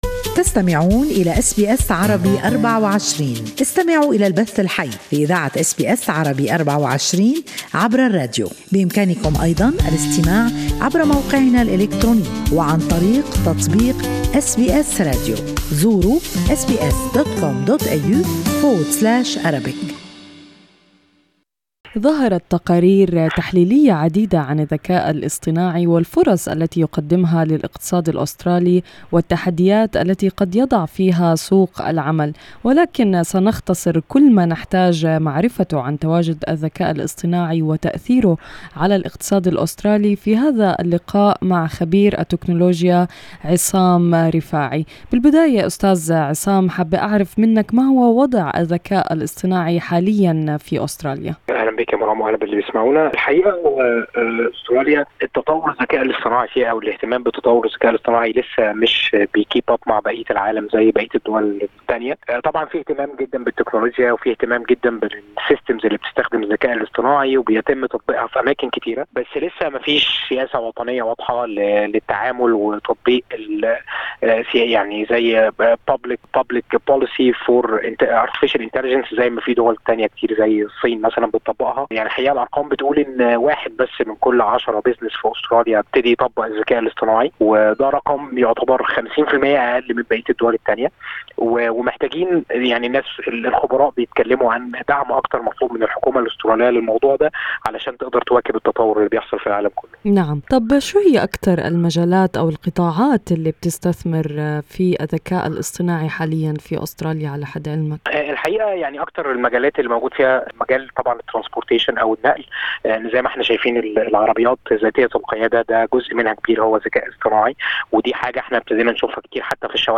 في هذا اللقاء الصوتي